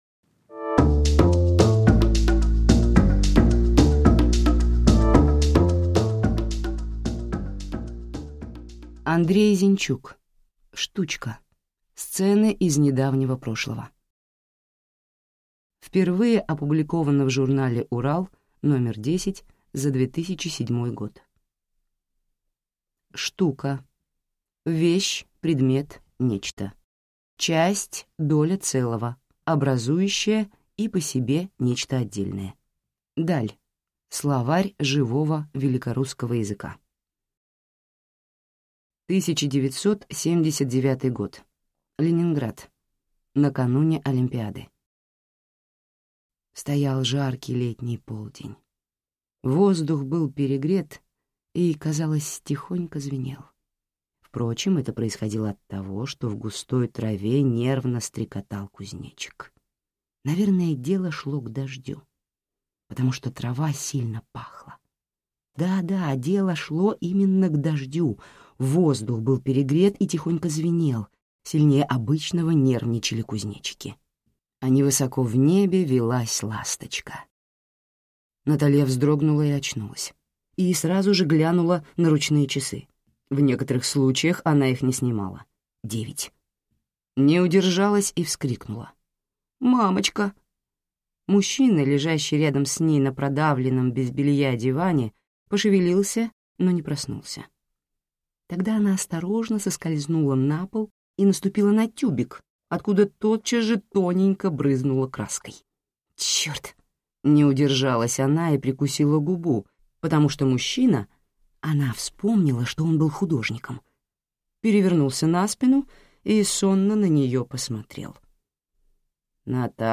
Аудиокнига Штучка. Сцены из недавнего прошлого.